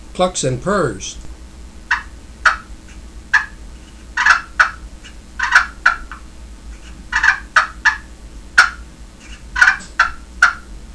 Listen to 11 seconds of clucks & purrs
For close work, when low volume yelps, clucks and purring are required, nothing beats a good push-pin box, especially one made from wood.
wwpushpincluckspurrs11.wav